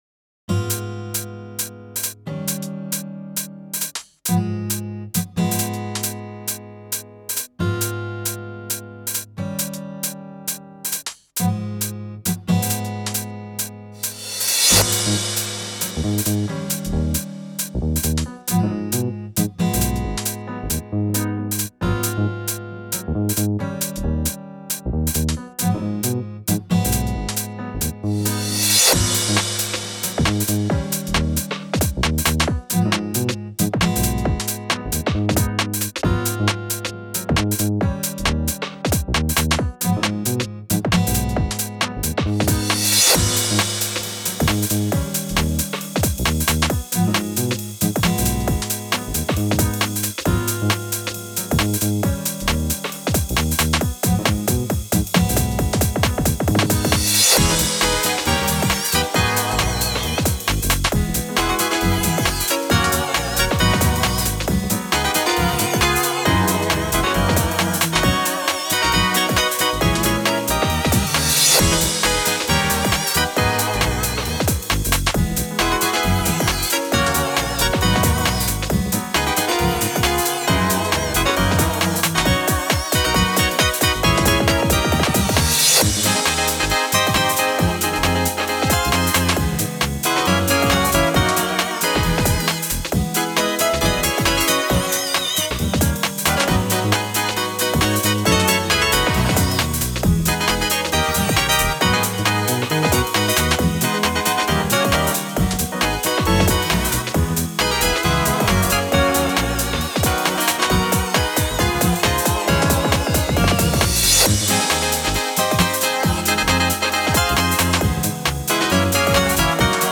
Ordinary 2step
企画の趣旨は同じコード進行で２つのジャンル曲を作るというものです。